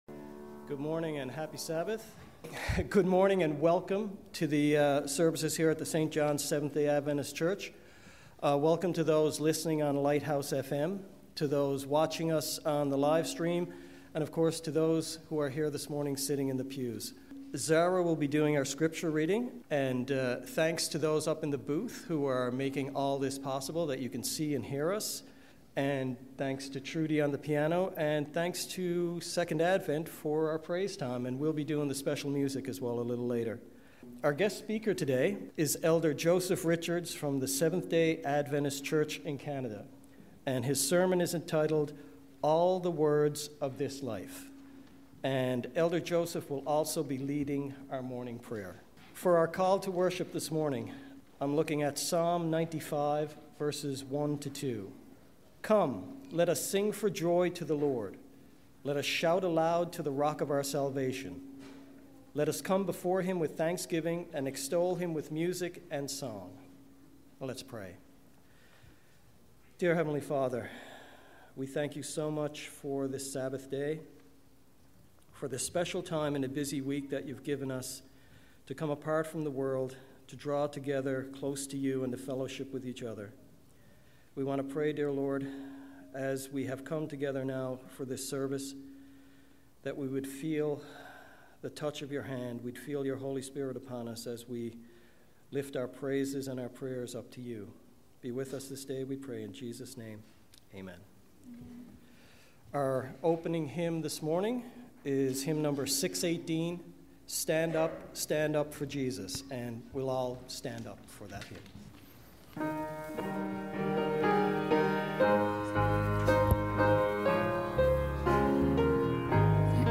Sabbath service of the St. John's Seventh-day Adventist Church